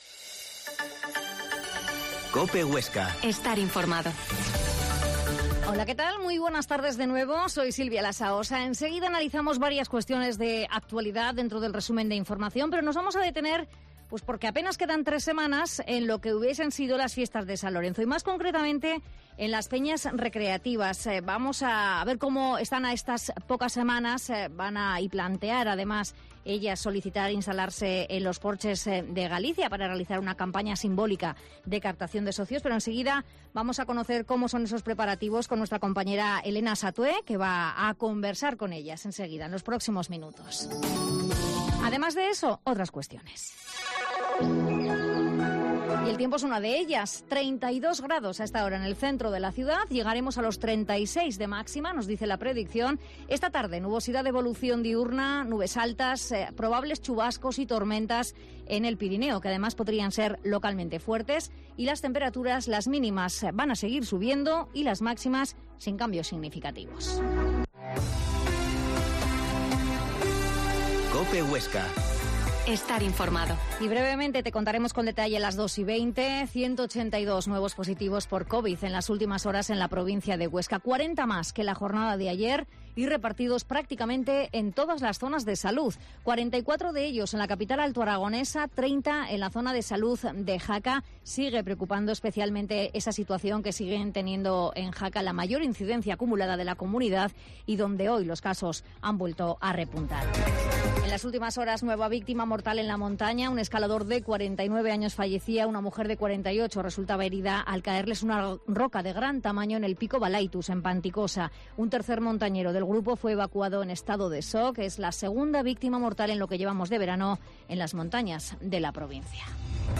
Herrera en COPE Huesca 13.20h. Entrevista a los presidentes de las peñas oscenses